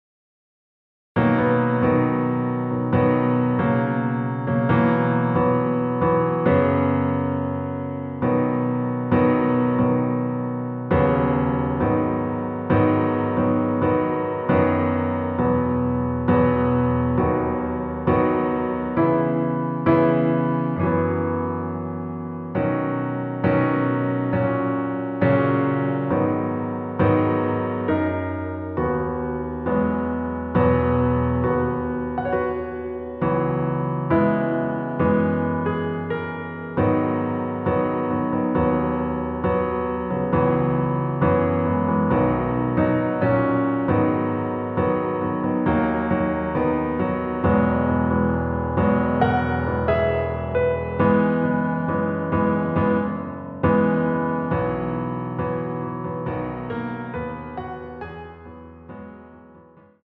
Db
반주를 피아노 하나로 편곡하여 제작하였습니다.
원키에서(-2)내린 (Piano Ver.) MR입니다.